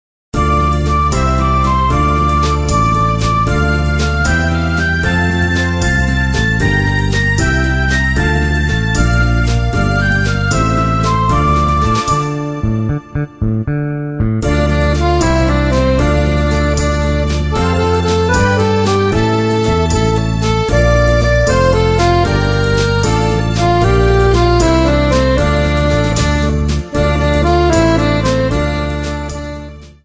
accordion